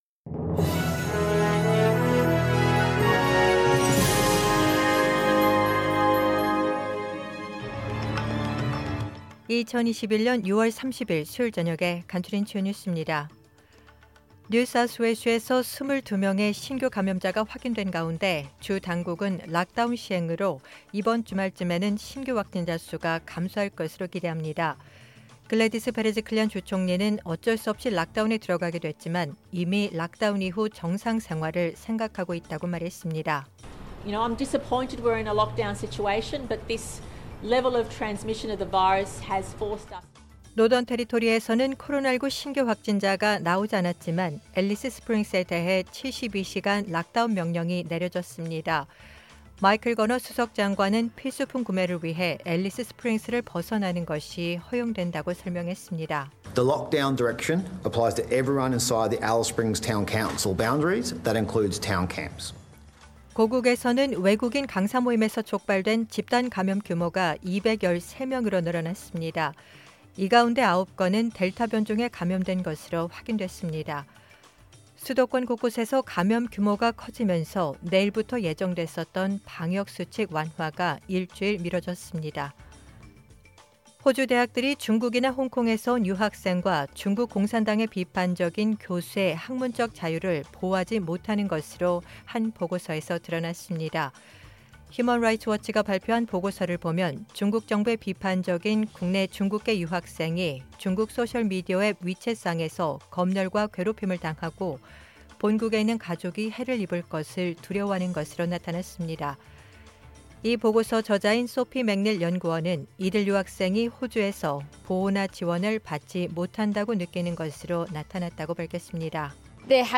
2021년 6월 30일 수요일 저녁의 SBS 뉴스 아우트라인입니다.